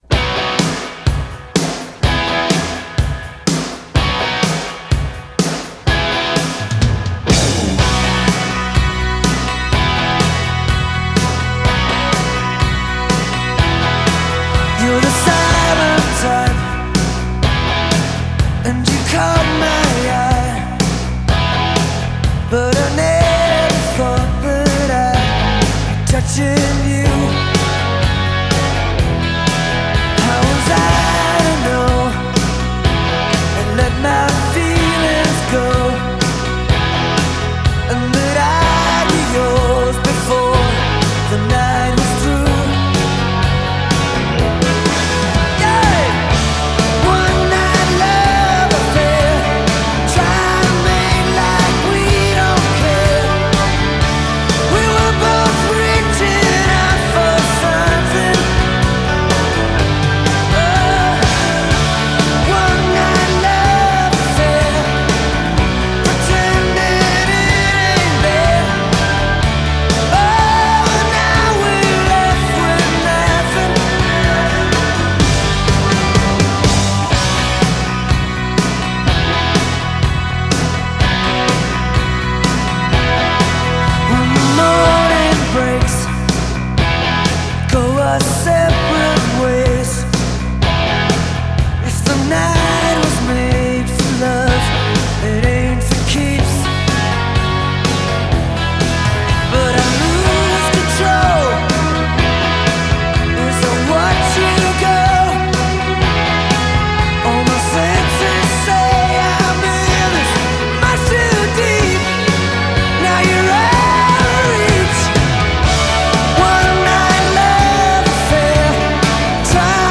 Rock / Classic Rock